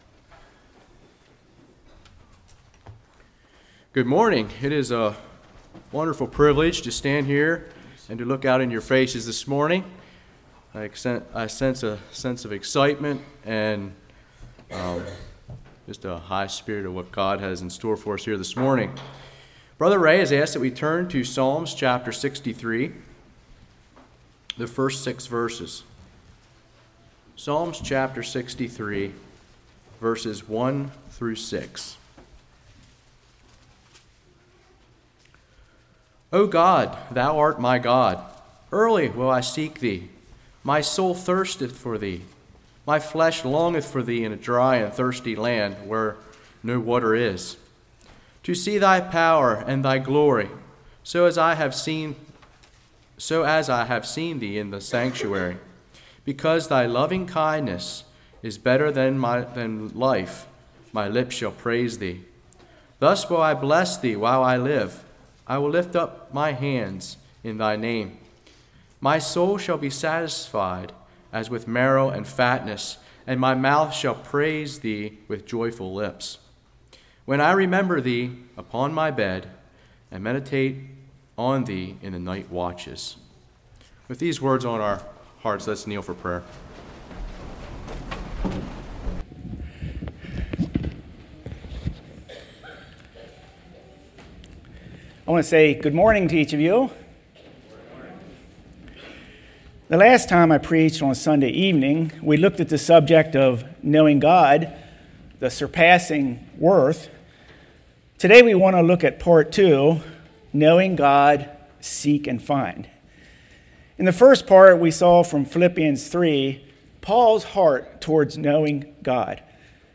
Psalms 63:1-6 Service Type: Morning Knowing God